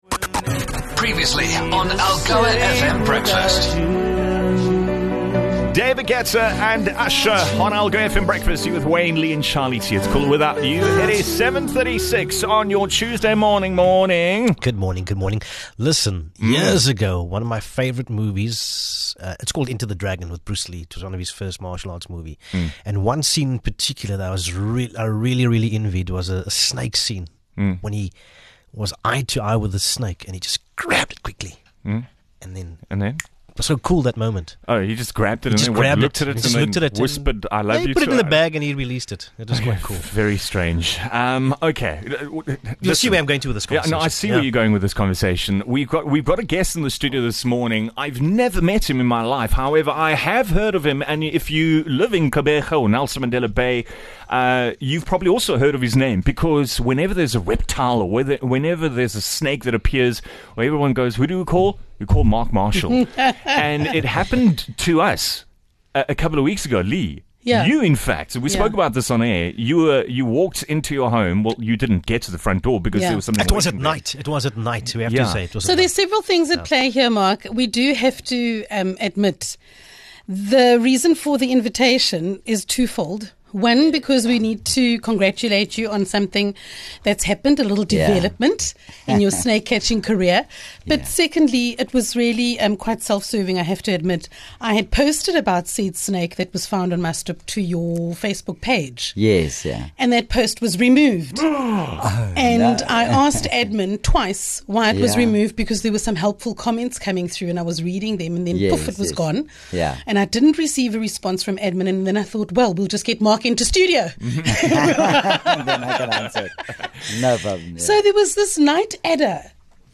MultiMedia LIVE